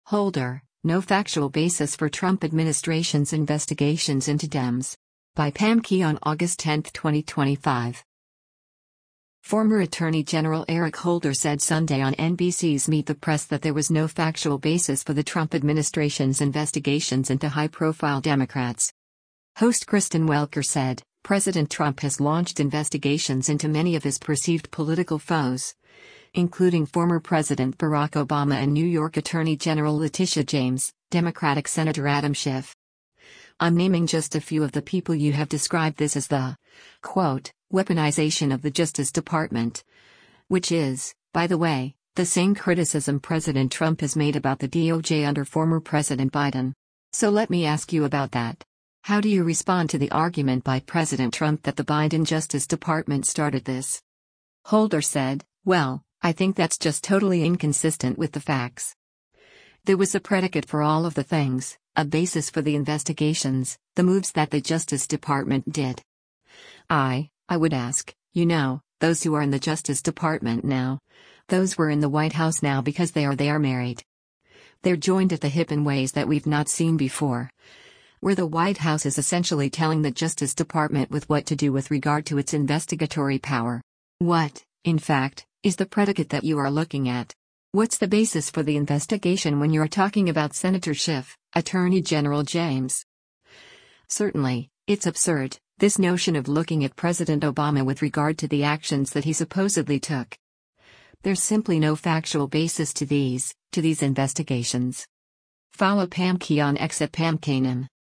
Former Attorney General Eric Holder said Sunday on NBC’s “Meet the Press” that there was “no factual basis” for the Trump administration’s investigations into high-profile Democrats.